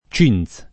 vai all'elenco alfabetico delle voci ingrandisci il carattere 100% rimpicciolisci il carattere stampa invia tramite posta elettronica codividi su Facebook cinz [ © in Z ] (meglio che cintz [id.]) s. m. — cfr. chintz